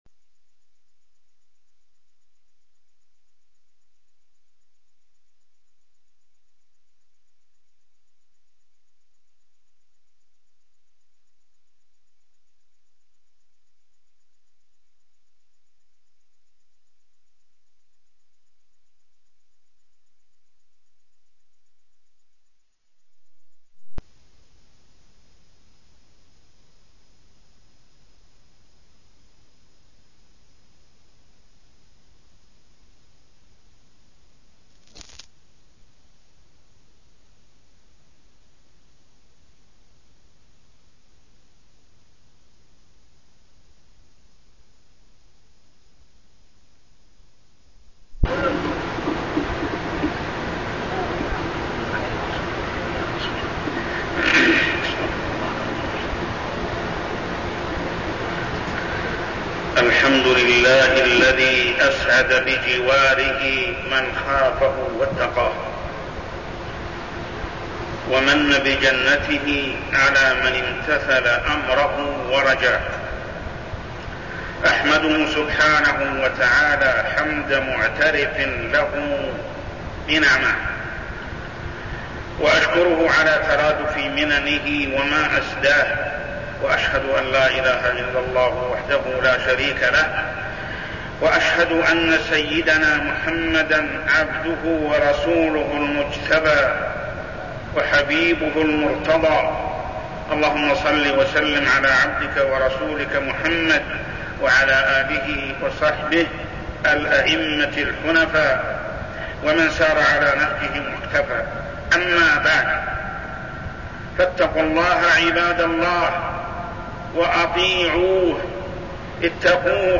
تاريخ النشر ١١ صفر ١٤١٤ هـ المكان: المسجد الحرام الشيخ: محمد بن عبد الله السبيل محمد بن عبد الله السبيل حق الجار